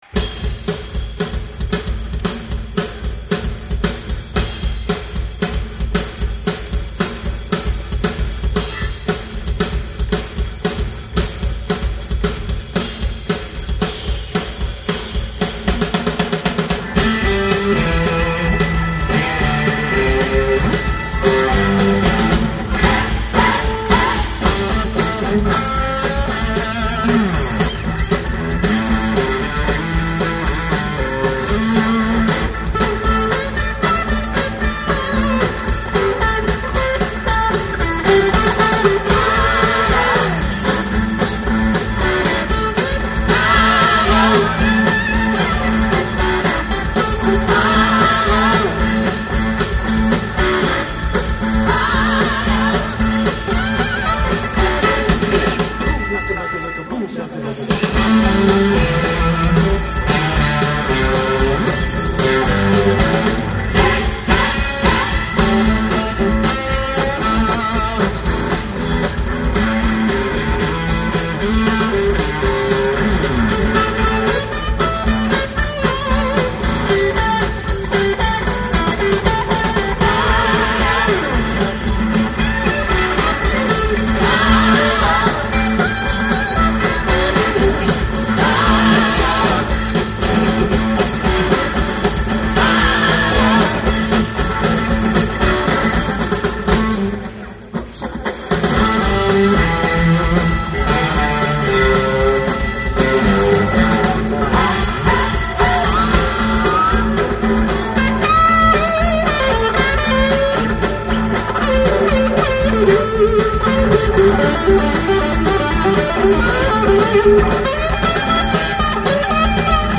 ■2月22日の歴史的ジョイントコンサートの二日目の
最近のオーディエンスは音が良いですね♪
二人のギターも細部まで分かって当日を思い出します。